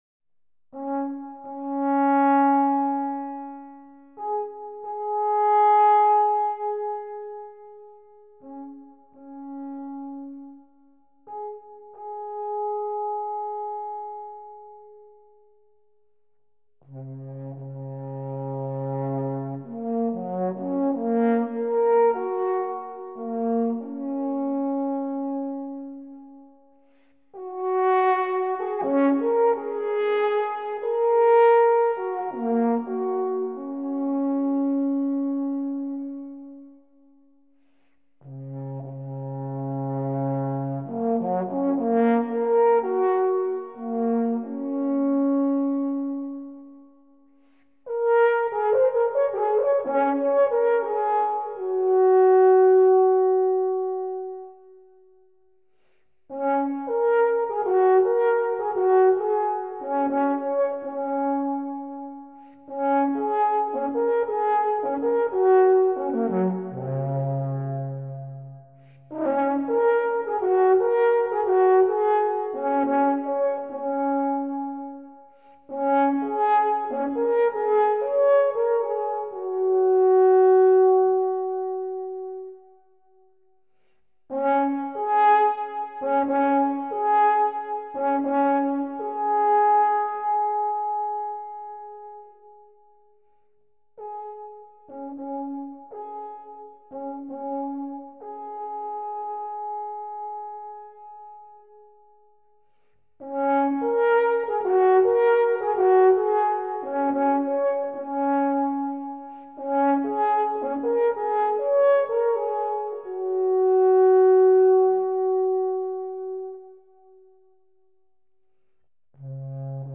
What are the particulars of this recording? A Swiss National Yodeling Festival